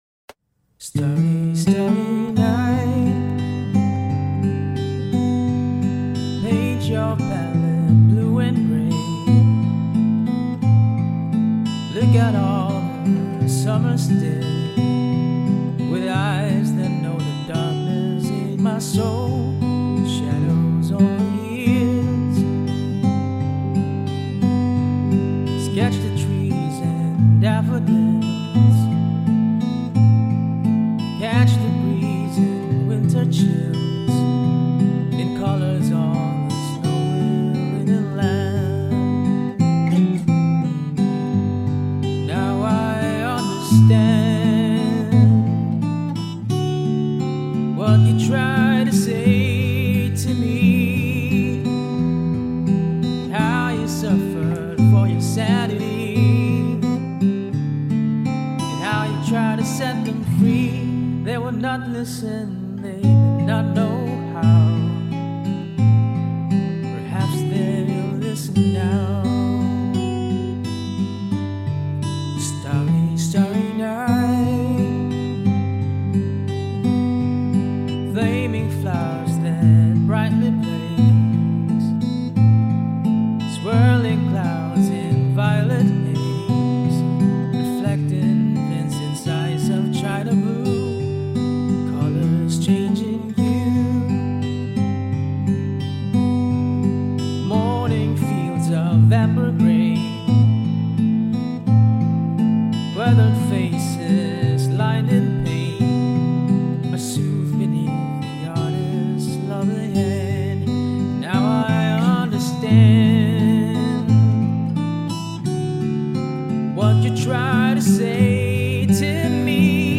Audio Clip from the Tutorial
Standard Tuning - 4/4 Time